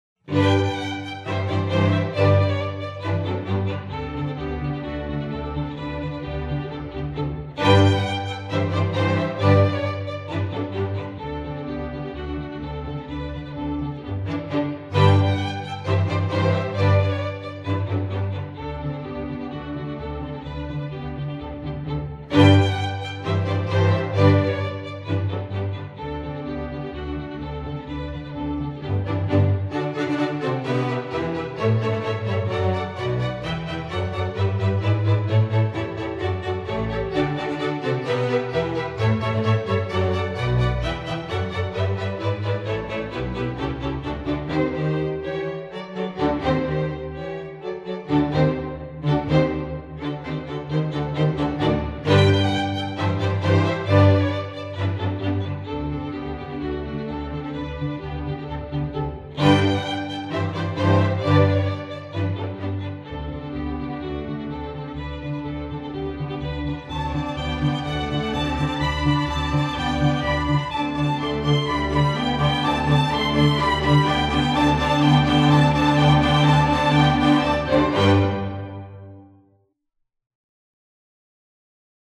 classical, children
Piano accompaniment part: